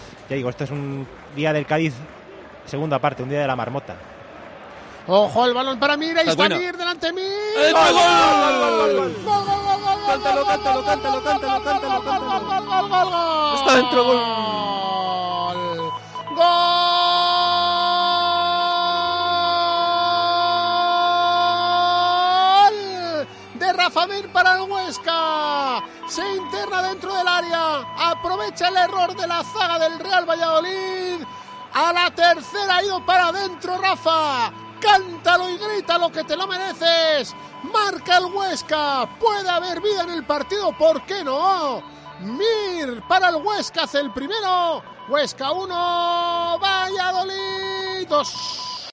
Narración Gol de Rafa Mir / Huesca 1-2 Real Valladolid